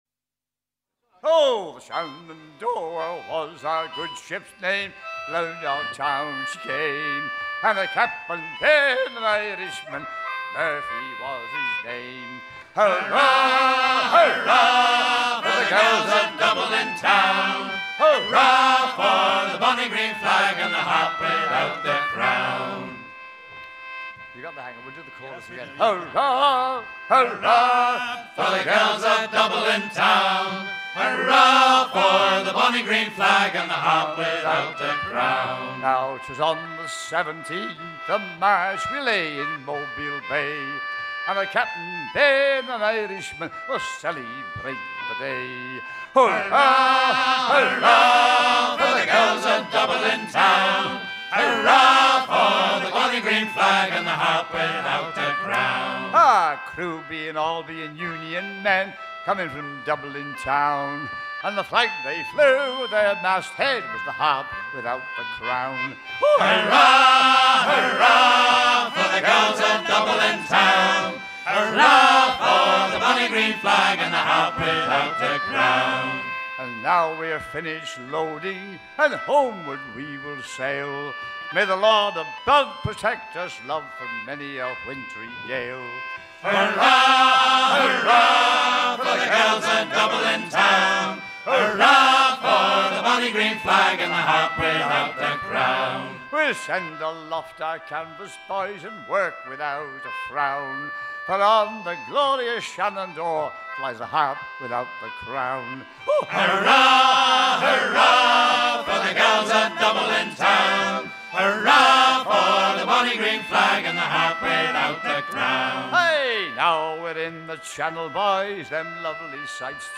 chant très populaire auprès des marins de liverpool
à virer au cabestan
Pièce musicale éditée